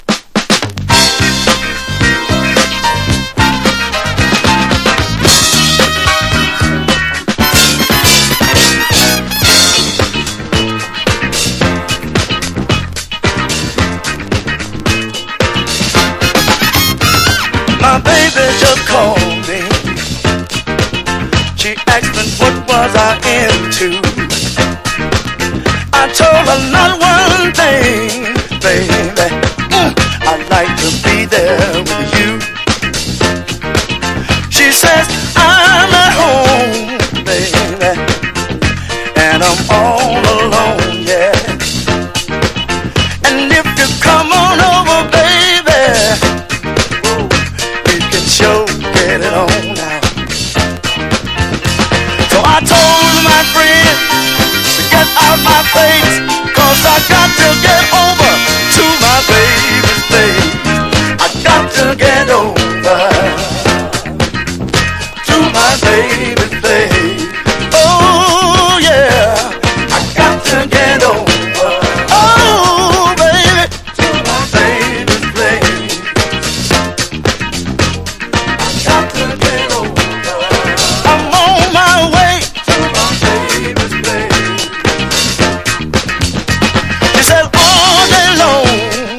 # RARE GROOVE# FUNK / DEEP FUNK